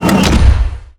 footstep1.wav